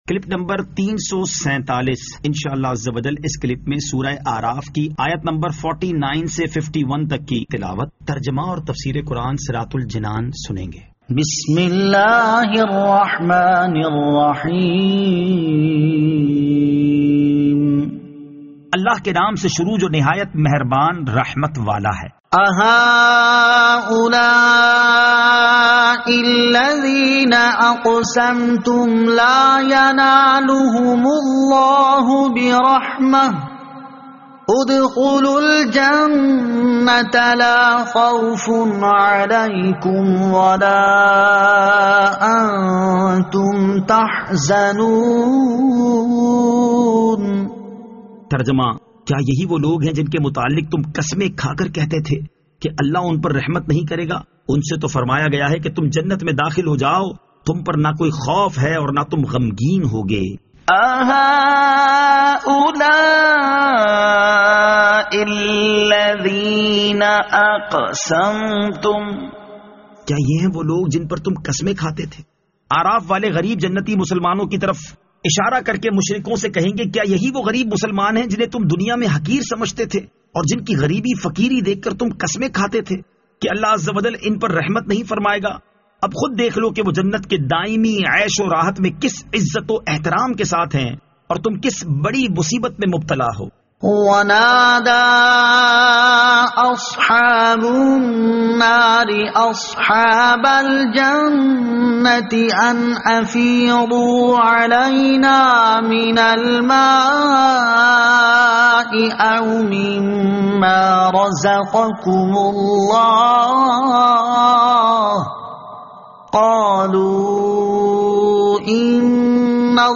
Surah Al-A'raf Ayat 49 To 51 Tilawat , Tarjama , Tafseer